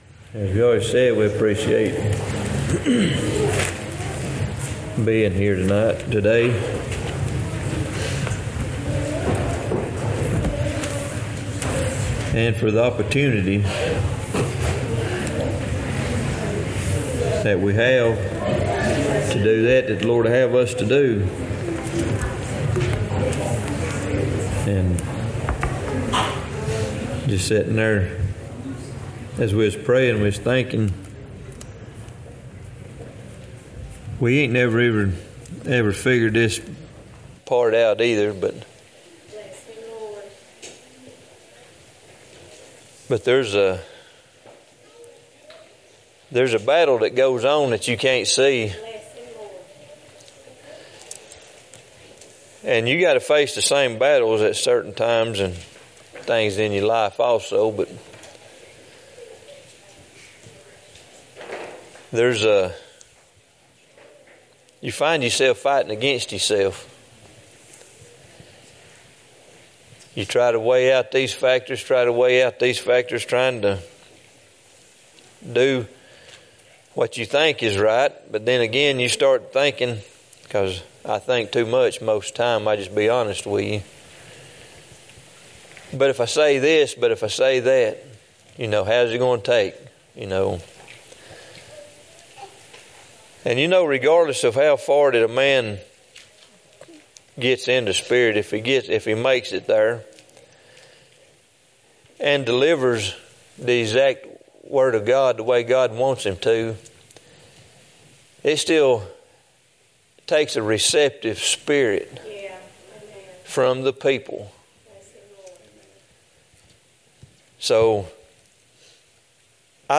2024 Passage: Romans 12:1-5 1 Corinthians 1:10-17 Service Type: Sunday Topics